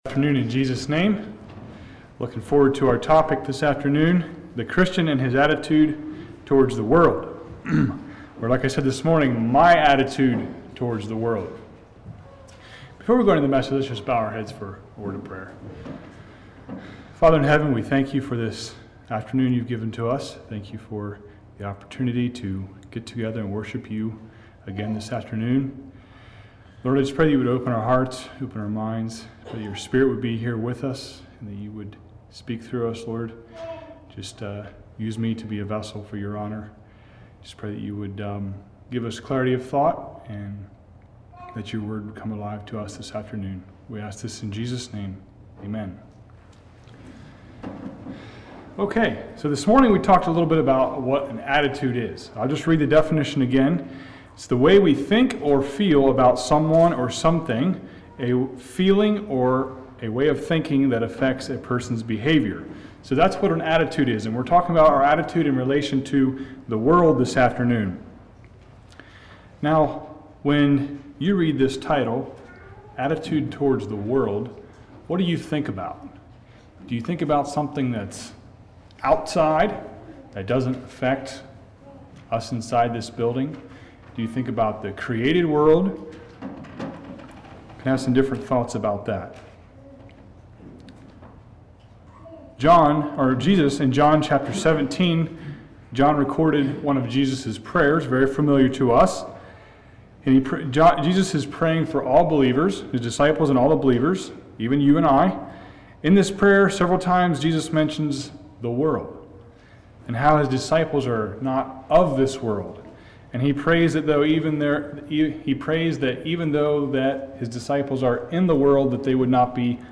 God has called us out to be separate from this world. This sermon has 6 points on helps to accomplish a right attitude toward the world.